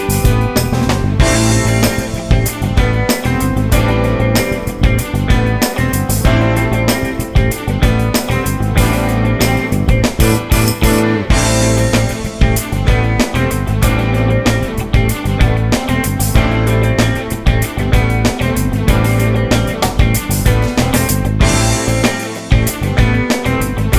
Live - no Backing Vocals Pop (1980s) 4:26 Buy £1.50